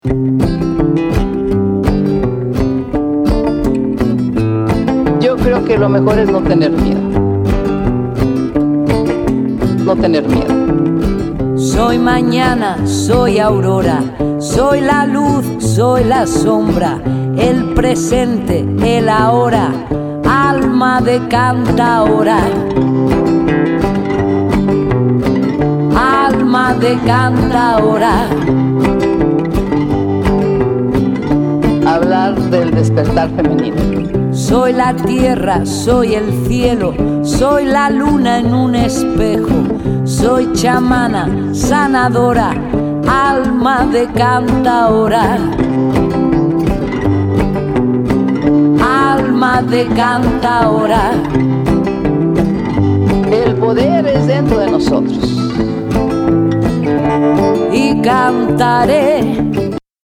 WORLD / CD